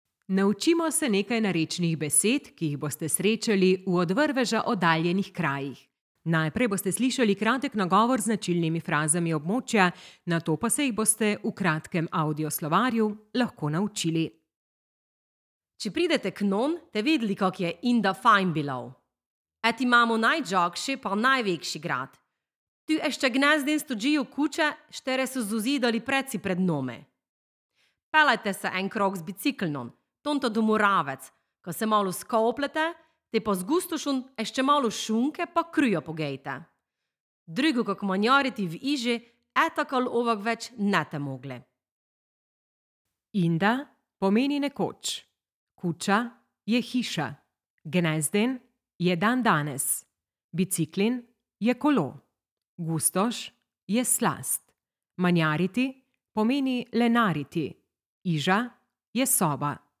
Goričko - Dialect